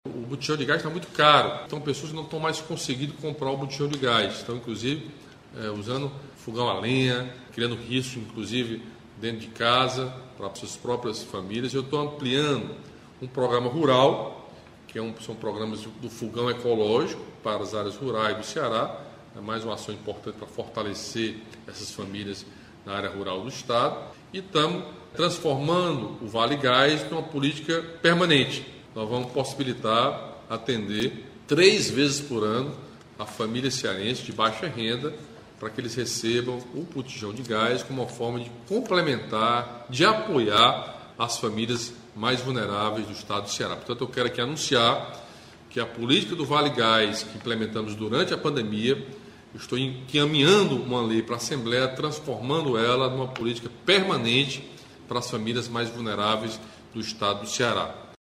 Em live semanal nas redes sociais, o governador Camilo Santana assinou, nesta terça-feira (24), um projeto de lei que transforma o Vale Gás Social em política pública permanente.